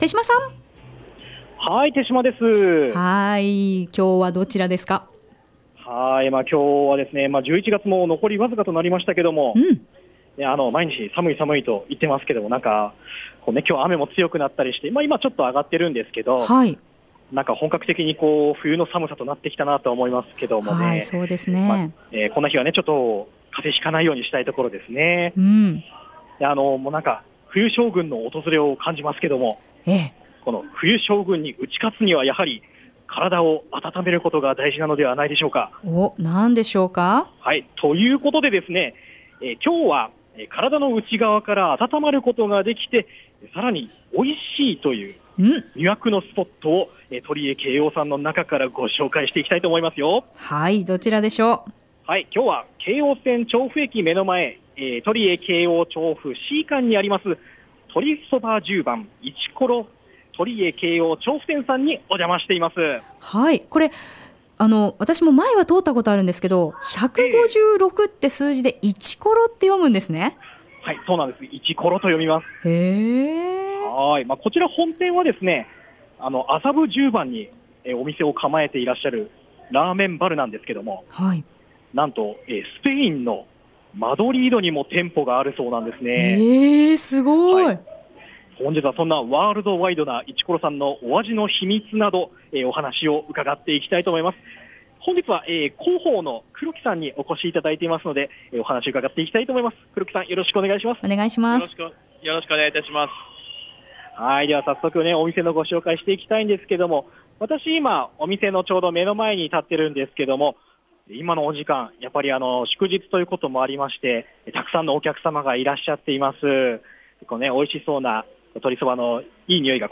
（写真はクリックで拡大します） いつも賑わっていますね～ 本日の放送音声はコチラ↓ 午後のカフェテラス 街角レポート 2017-11-23(木) 寒いこの季節はやはりラーメンは外せません。